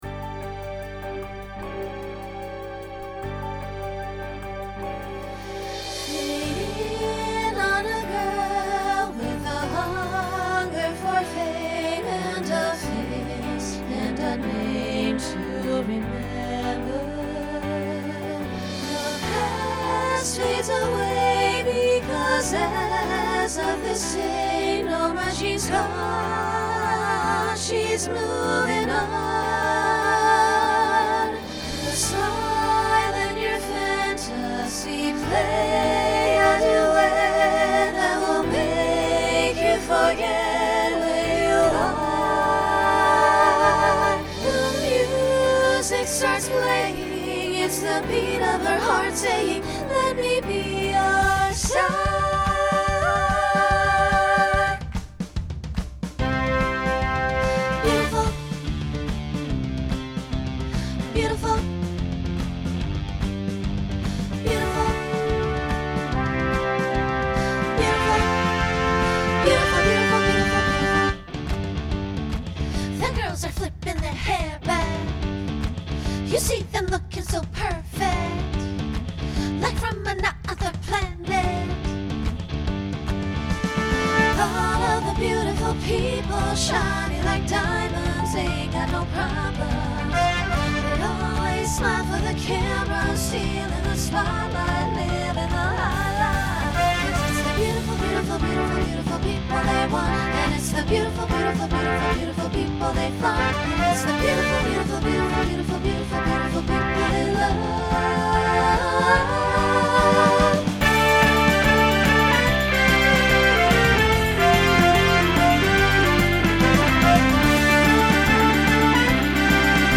Broadway/Film , Pop/Dance Instrumental combo
Voicing SSA